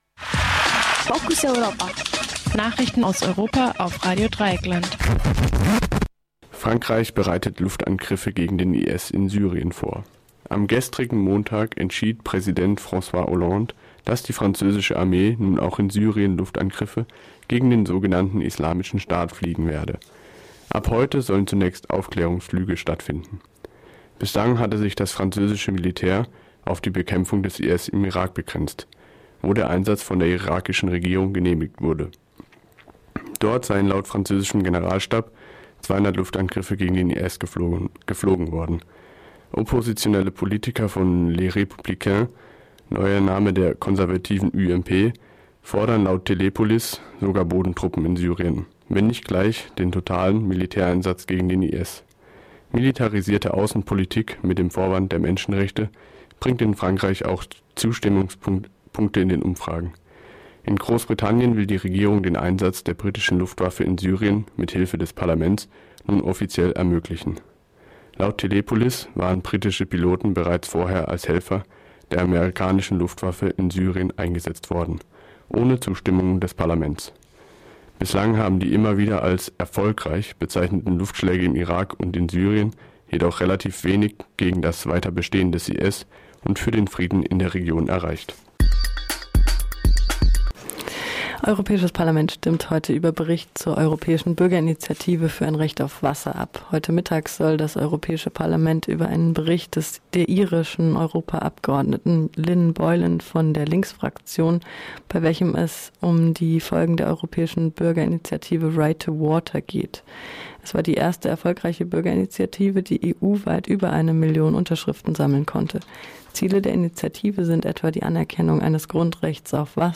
Focus Europa Nachrichten am Dienstag, 8. September 2015